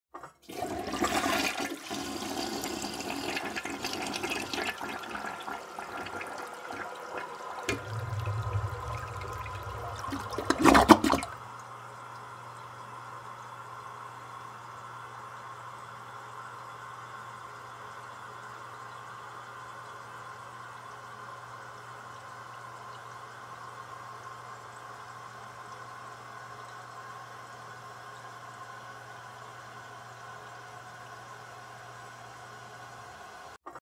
Звуки смыва унитаза